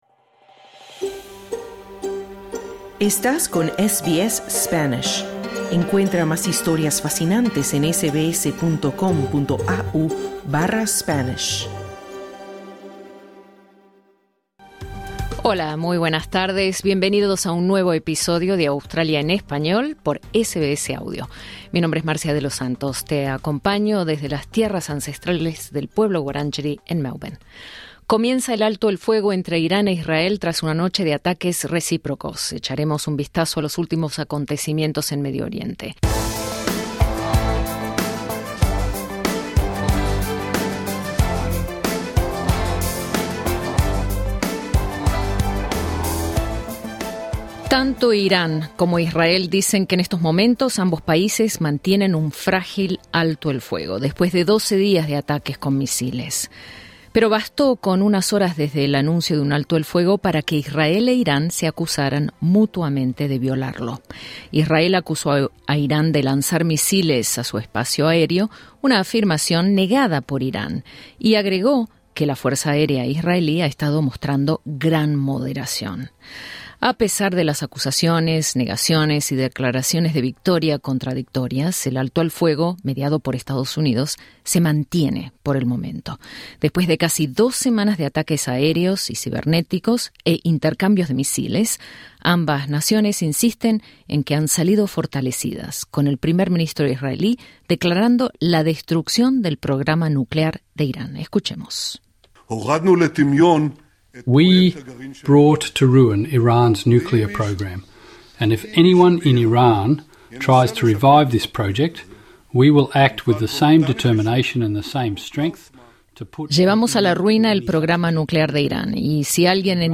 Con gran enfado, el presidente de Estados Unidos, Donald Trump advirtió a Israel que no lance más bombas sobre Irán mientras intenta mantener en vigor un frágil alto el fuego entre los dos países de Medio Oriente, pactado el martes. Escucha nuestro informe.